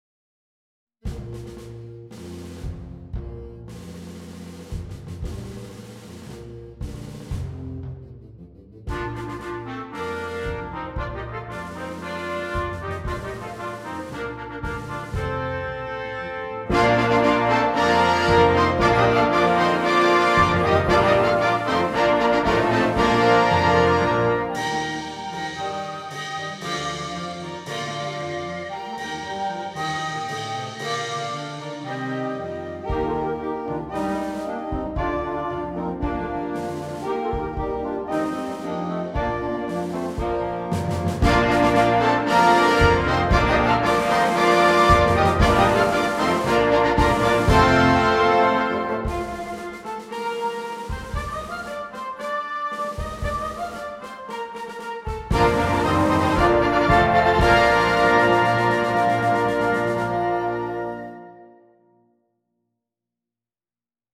für großes Blasorchester